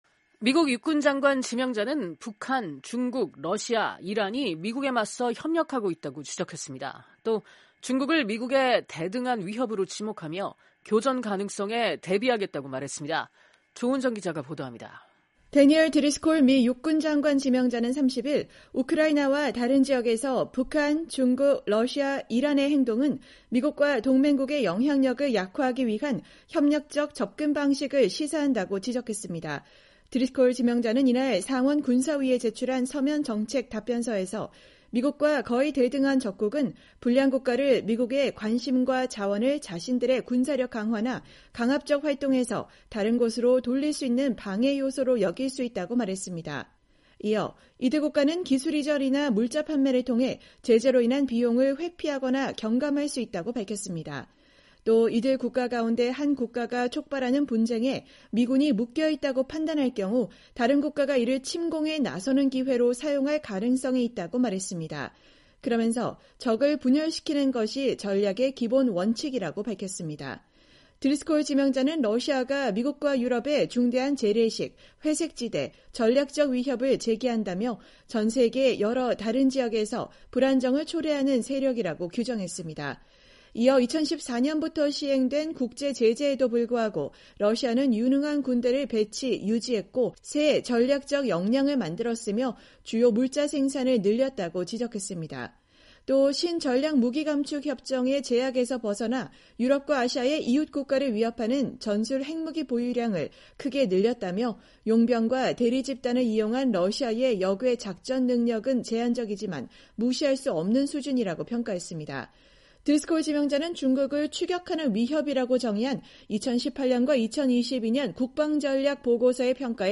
대니얼 드리스콜 미 육군장관 지명자가 2025년 1월 30일 상원 군사위 인준 청문회에서 발언하고 있다.